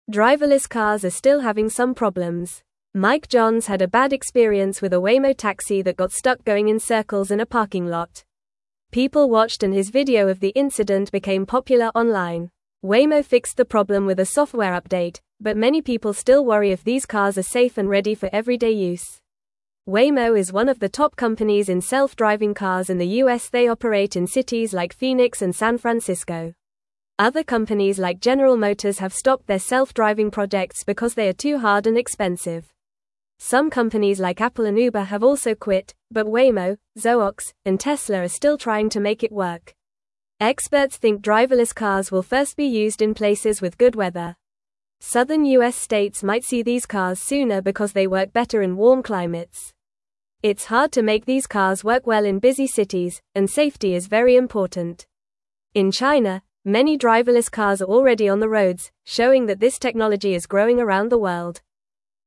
Fast
English-Newsroom-Lower-Intermediate-FAST-Reading-Driverless-Cars-Safe-or-Not-for-Everyone.mp3